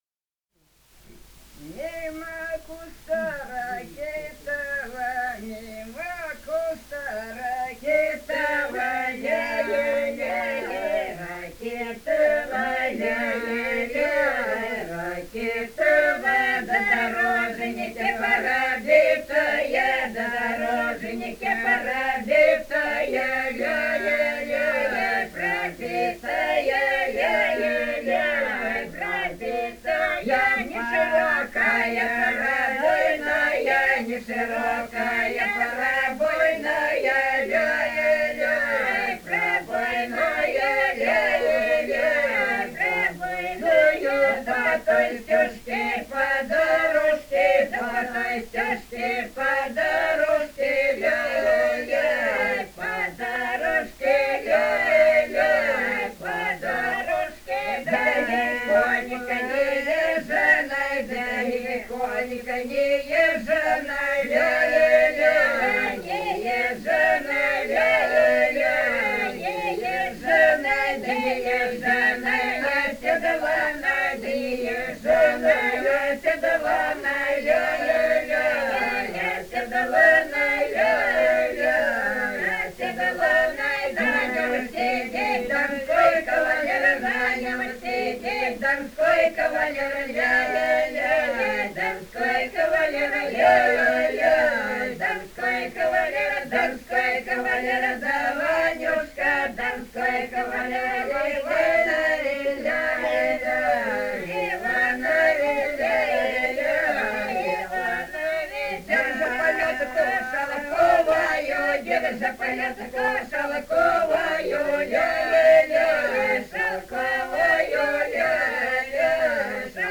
полевые материалы
«Мимо куста ракитова» («на вечеринке до свадьбы»).
Ростовская область, г. Белая Калитва, 1966 г. И0940-14